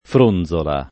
[ fr 1 n z ola ]